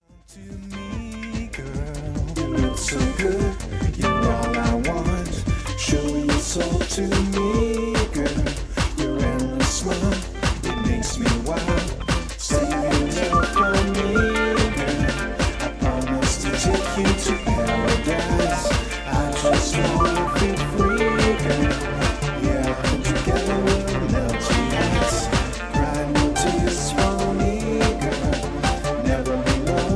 housy pop dance tune